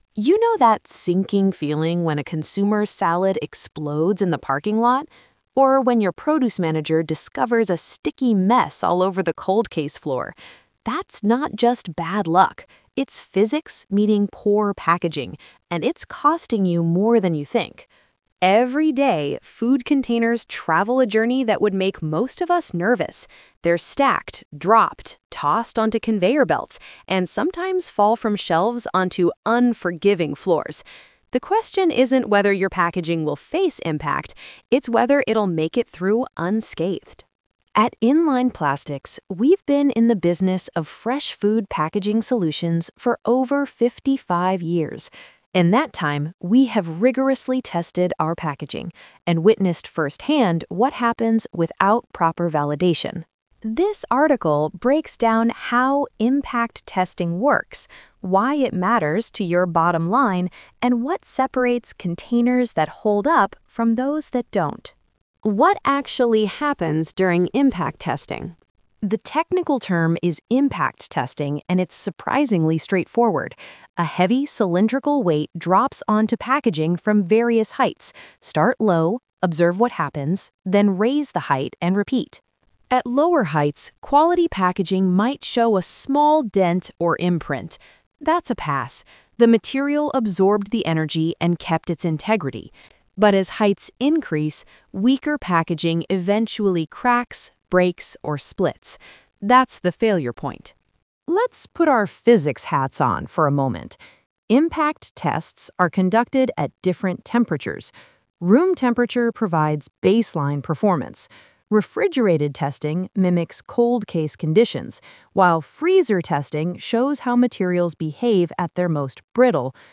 Impact-Testing-The-Hidden-Secret-to-Packaging-That-Actually-Works-Dictation.mp3